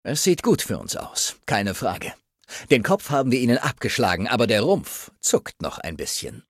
Broken Steel: Audiodialoge